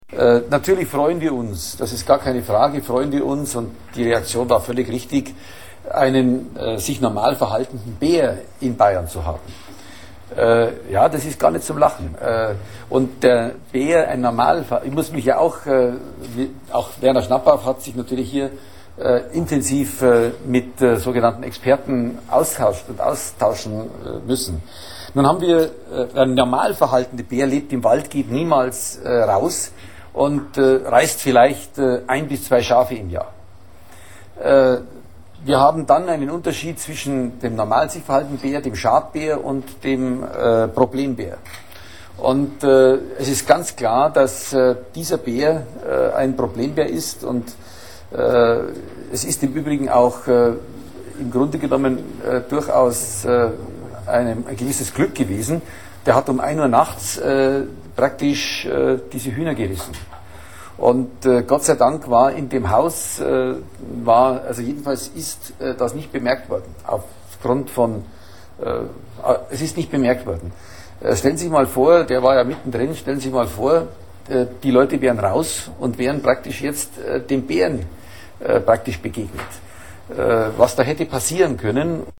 Stoibaer berichtet in rhetorisch gewohnt ausgefeilter Rede über "normal sich verhaltende Bären", "Problembären" und "Schadbären".
Hier der Originalton des bärigen Ausschnittes zum